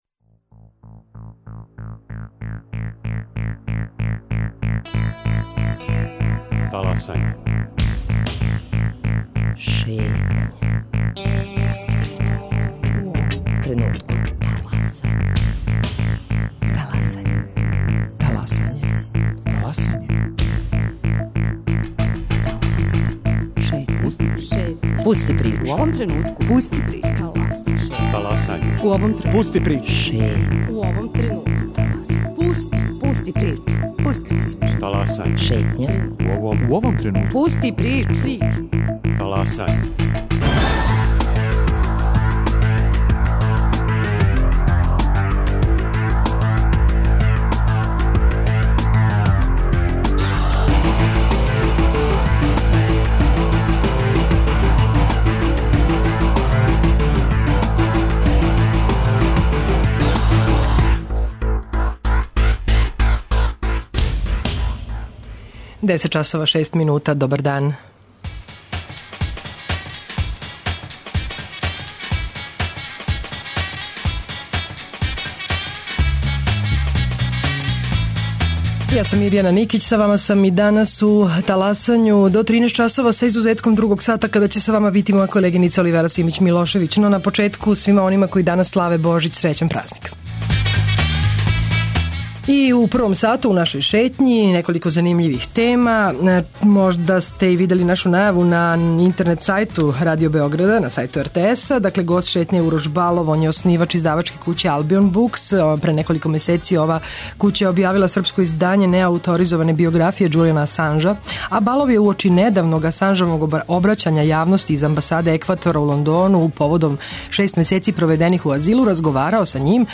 Чућете и две приче наших репортера: разговор са радницима металцима из Ужица, добитницима признања за врхунску мајсторску вештину, који су на 39. такмичењу металаца Србије освојили друга места и разговор са берберином из једног од малобројних старинских салона у Београду о томе како се некада учио фризерски занат.